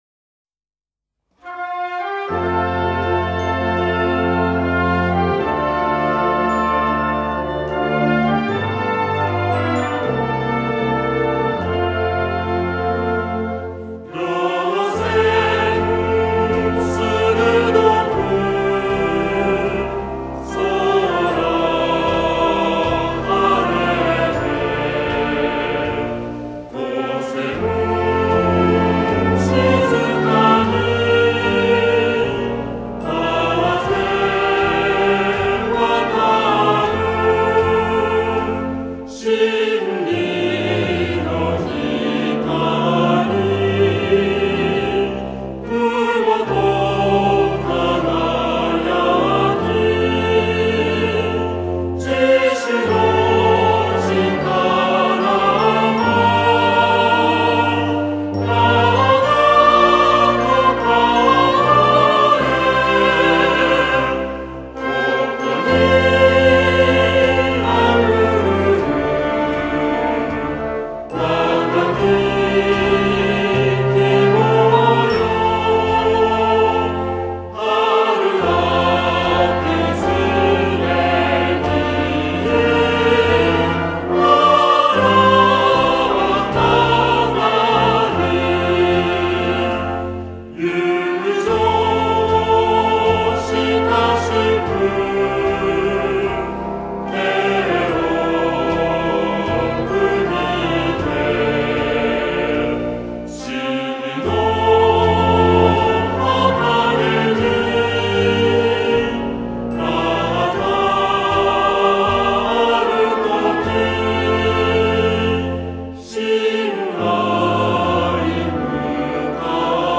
演奏／竹台高等学校吹奏楽部
校歌（歌あり）　　 　 　校歌（歌なし）